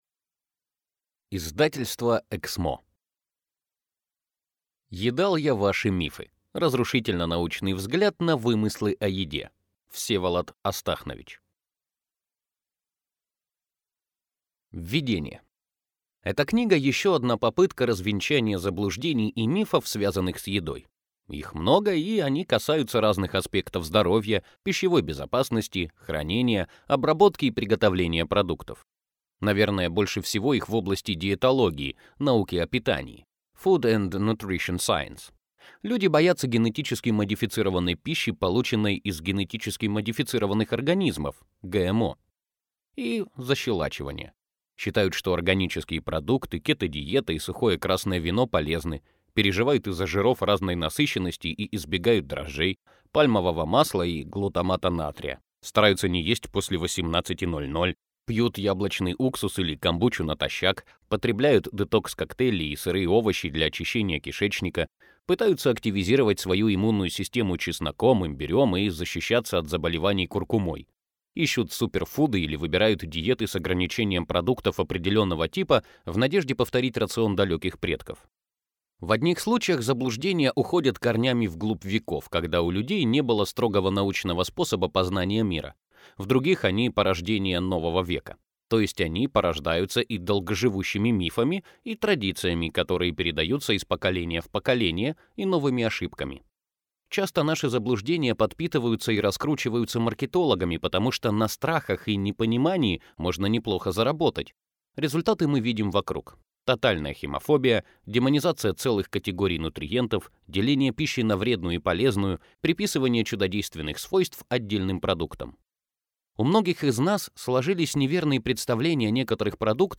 Аудиокнига Едал я ваши мифы. Разрушительно-научный взгляд на вымыслы о еде | Библиотека аудиокниг
Прослушать и бесплатно скачать фрагмент аудиокниги